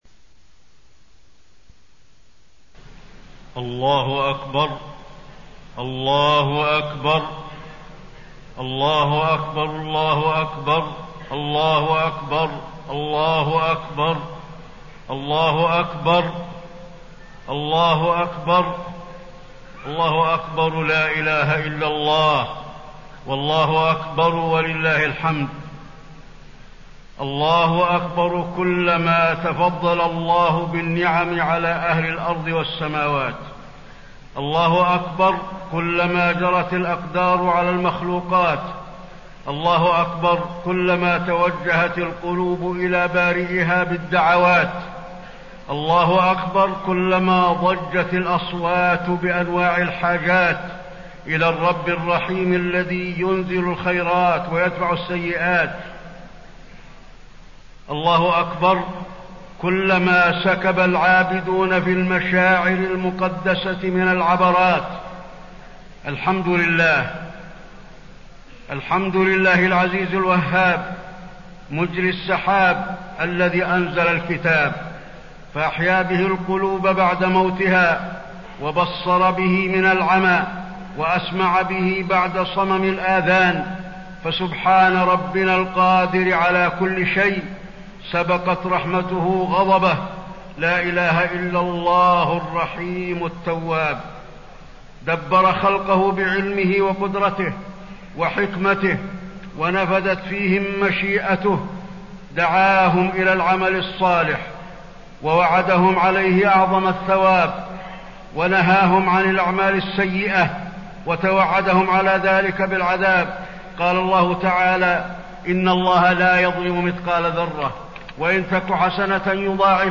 خطبة عيد الاضحى - المدينة - الشيخ علي الحذيفي
المكان: المسجد النبوي